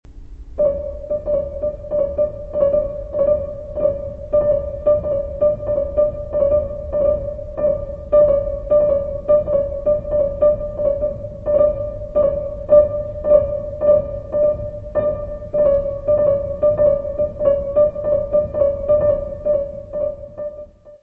: stereo; 12 cm + folheto
Music Category/Genre:  Classical Music
Three pieces for two pianos.